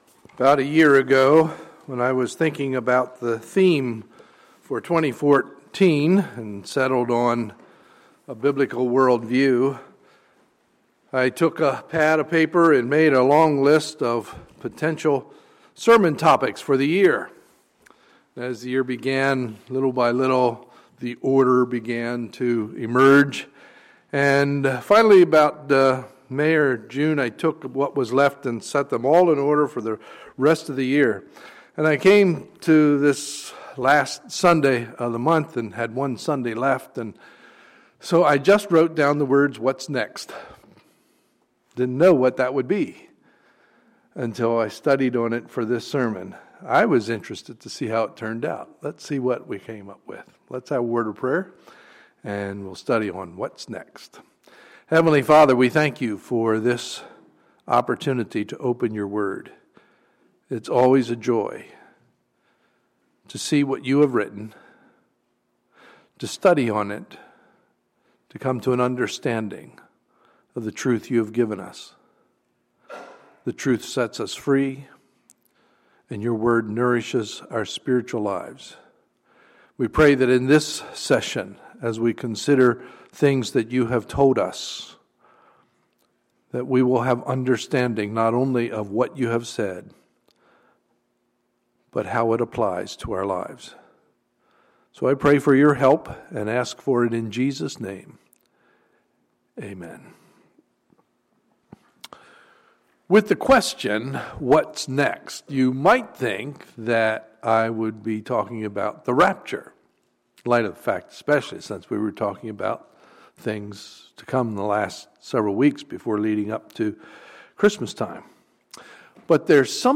Sunday, December 28, 2014 – Sunday Morning Service